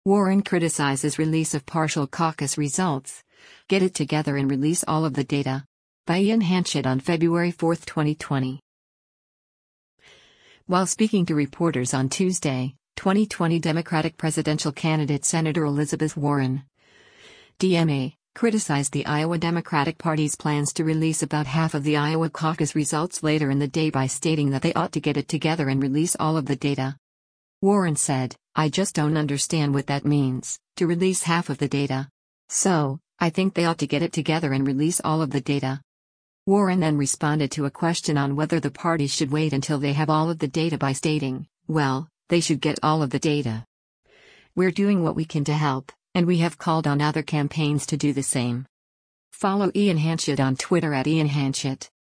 While speaking to reporters on Tuesday, 2020 Democratic presidential candidate Sen. Elizabeth Warren (D-MA) criticized the Iowa Democratic Party’s plans to release about half of the Iowa caucus results later in the day by stating that “they ought to get it together and release all of the data.”